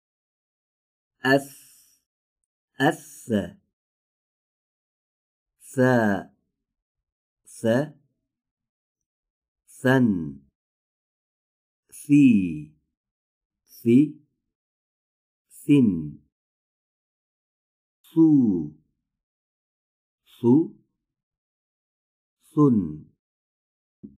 این حرف از برخورد سر زبان به پشت سردندان‌های پیشین بالا تلفظ می‌شود.
هنگام تولید حرف «ث» هوای سازنده آن، از محل برخورد سر زبان و سردندان‌های پیشین بالا به طور سایشی خارج می‌گردد.